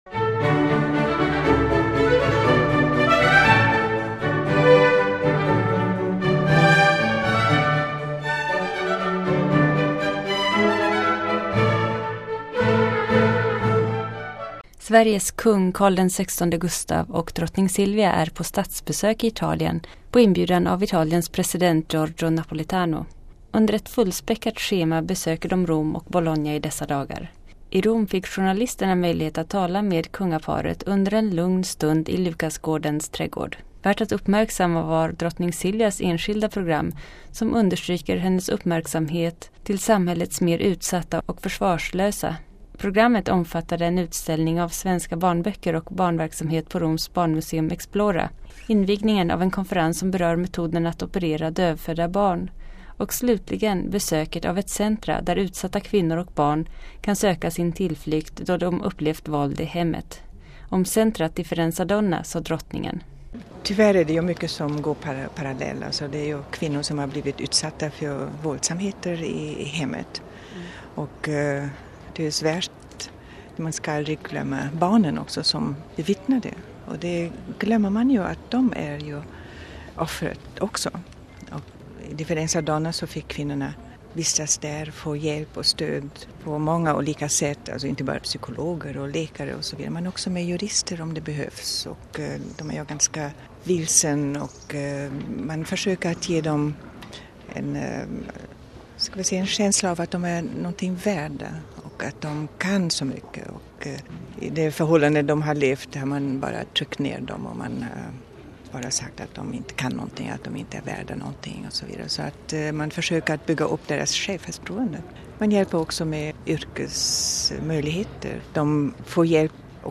Lyssna till Kung Carl XVI Gustaf och Drottning Silvia tala om deras statsbesök i Italien
(26.03.09) I trädgården på S:t Lukasgården i Rom fick journalister, däribland Vatikanradion, möjlighet att tala med Kung Carl XVI Gustaf och Drottning Silvia om deras statsbesök i Italien.